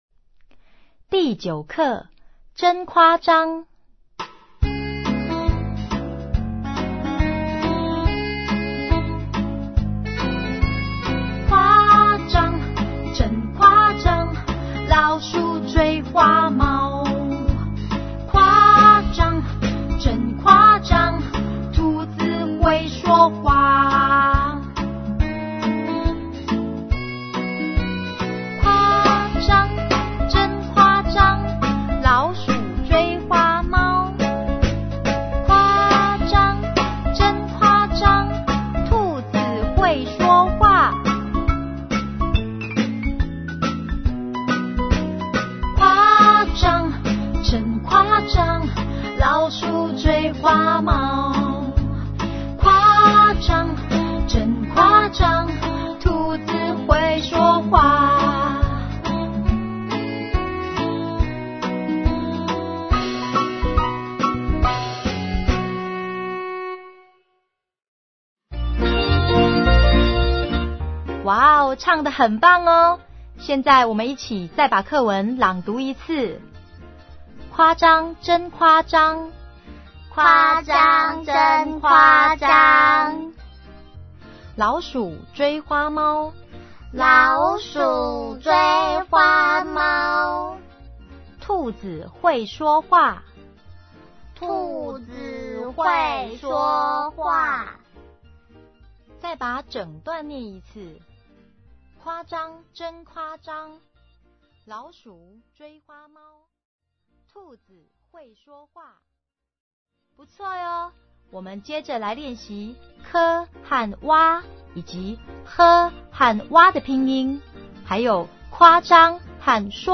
◎CD裡附有好聽的「拼音兒歌」